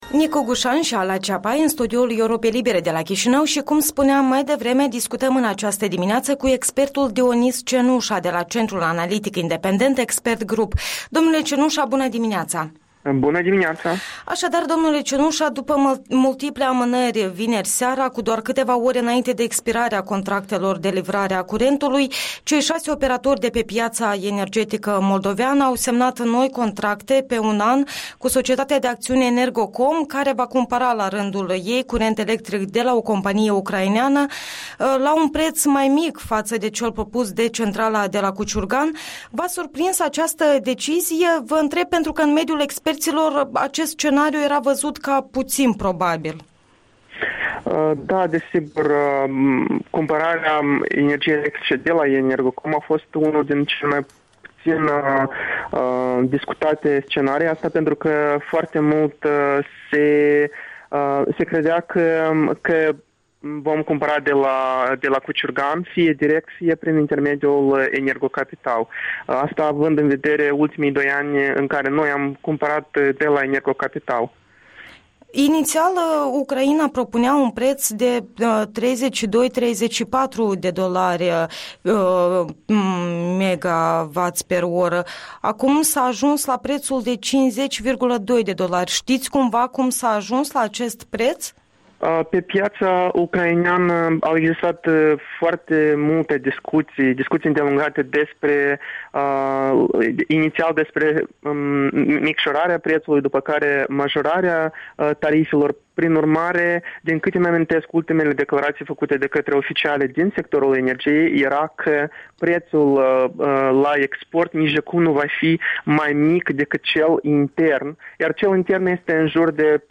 Interviul dimineții cu un expert de la Centrul analitic independent Expert Grup.